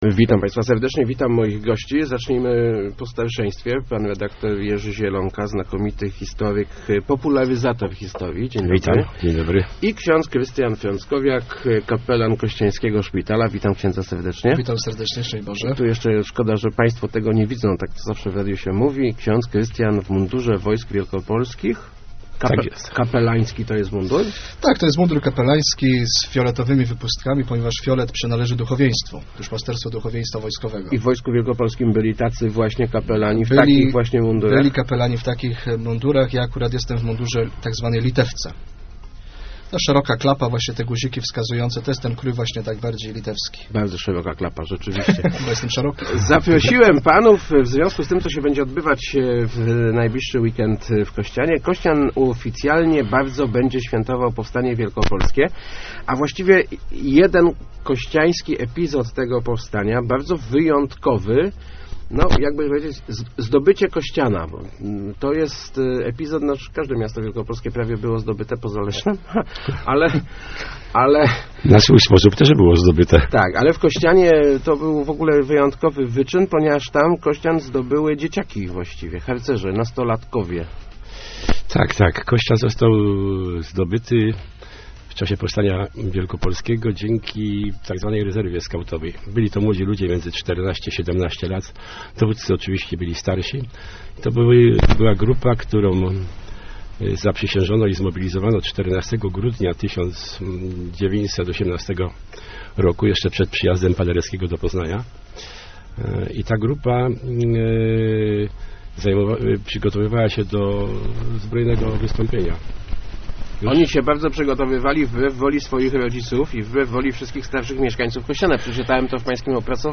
Leszno, Portal Regionu, regionalne, lokalne, radio, elka, Kościan, Gostyń, Góra, Rawicz, Wschowa, żużel, speedway, leszczyński, radio, gazeta, dodatek